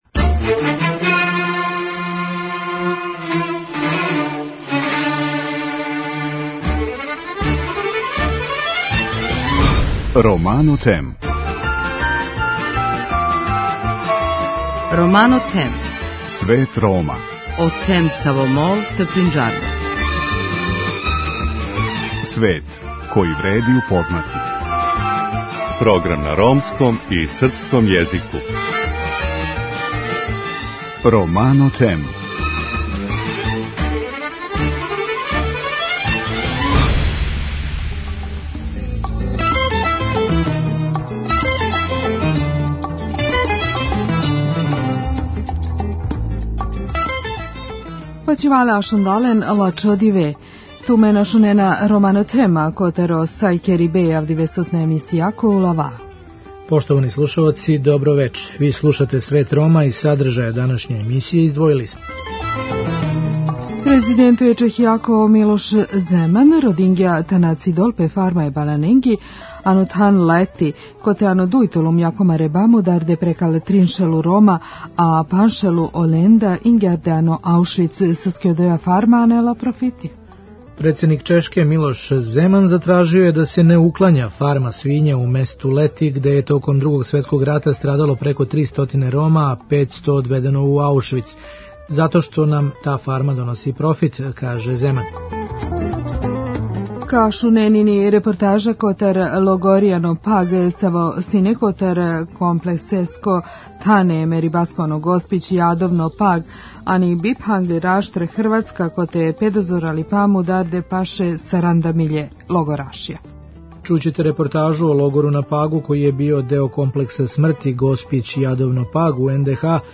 Приредили смо репортажу о логору на Пагу који је био део комплекса смрти Госпић-Јадовно-Паг, у НДХ у којем је мучки убијено око 40 хиљада логораша почетком 1941.